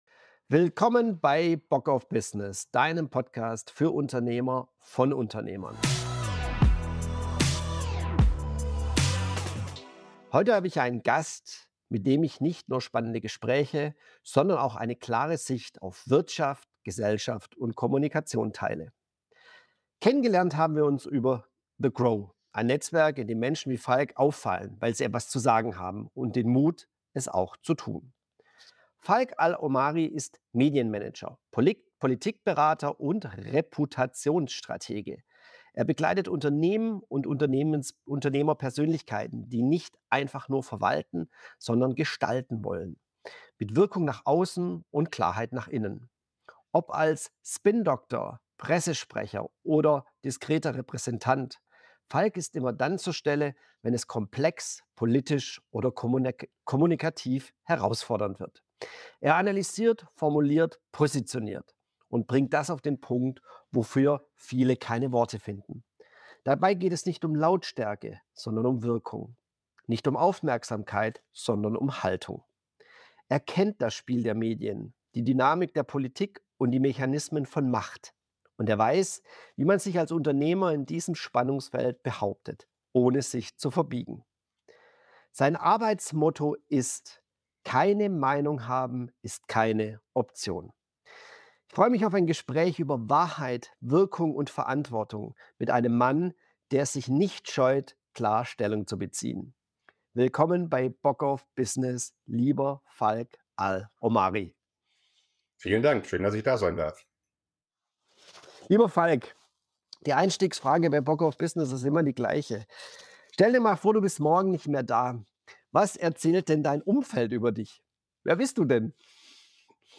Und ein Gespräch, das genau dort ansetzt, wo viele schweigen: bei der Verantwortung, die wir als Unternehmer tragen – für unser Denken, unsere Wirkung, unsere Stimme.